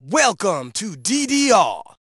Root > content > SFX & Announcers > DDR Extreme SFX